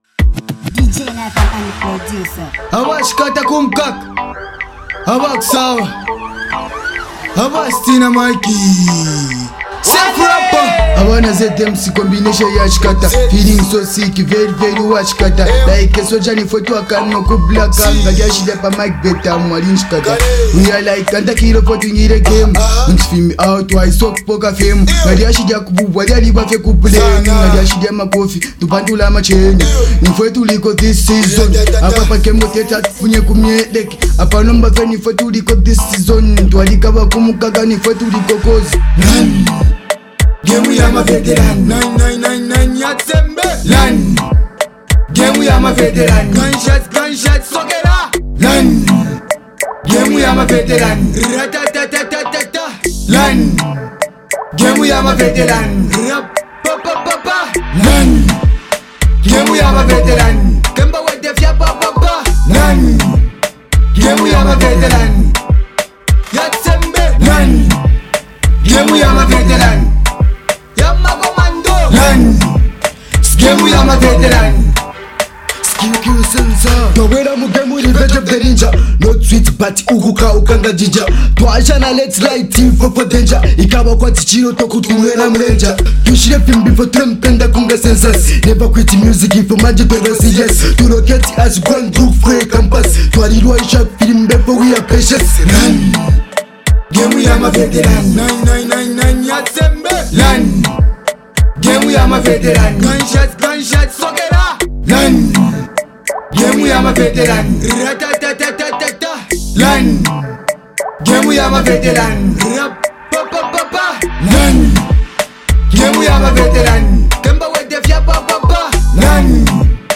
MusicZambian Music
confident and street-inspired track